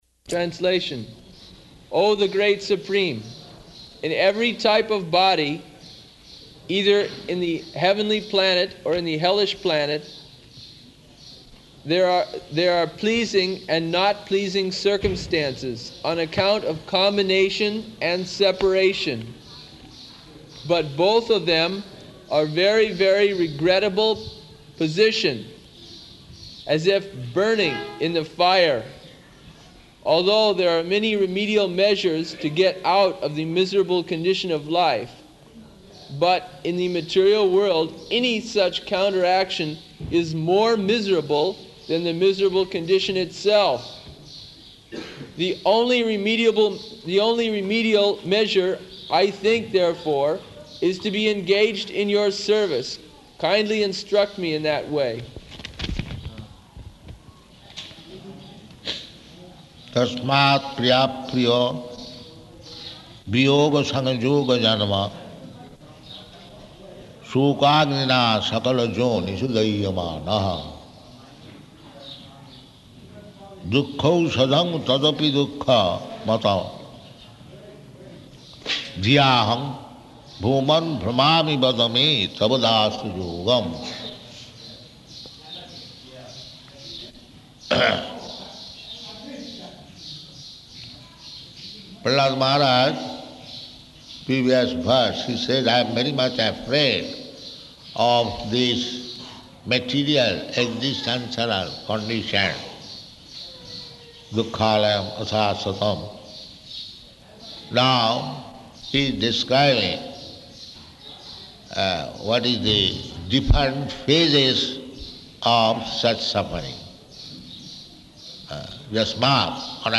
Type: Srimad-Bhagavatam
Location: Māyāpur